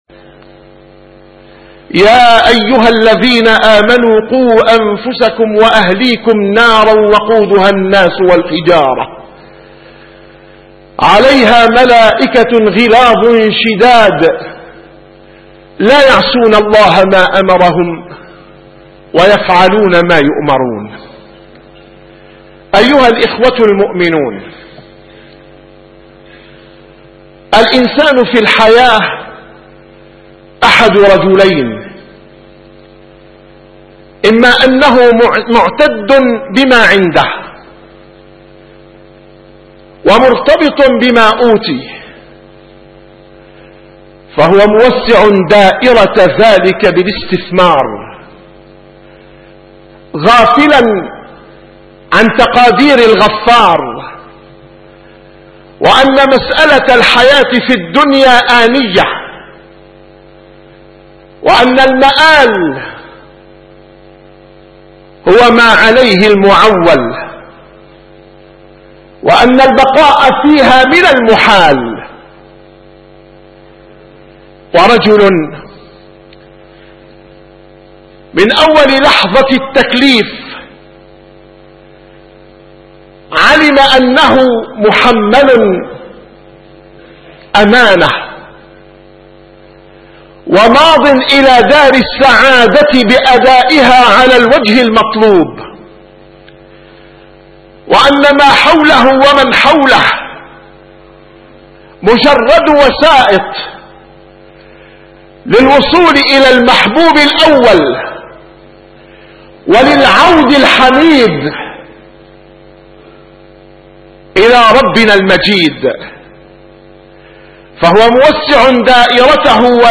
نسيم الشام › - الخطب